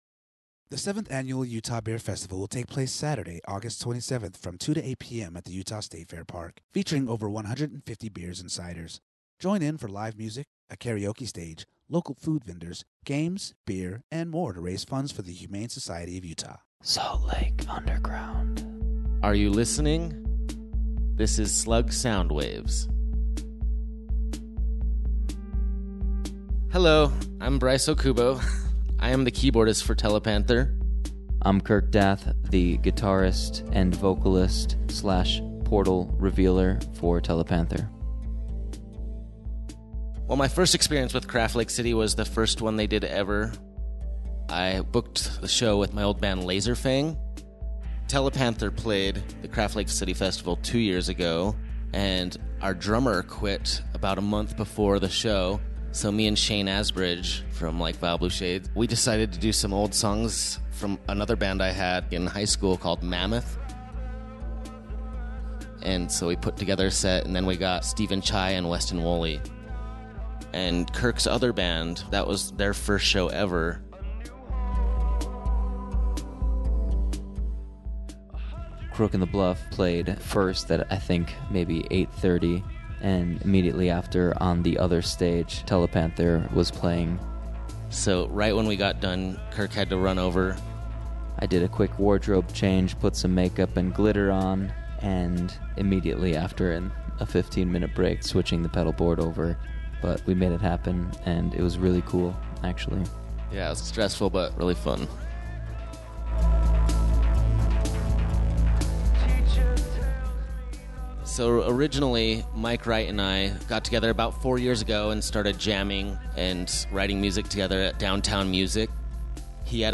Stories about traveling in space, leaving the planet, dance parties and sex—these are the elements, combined with synth/electro-pop instruments and robotic vocals, that make up local band Telepanther.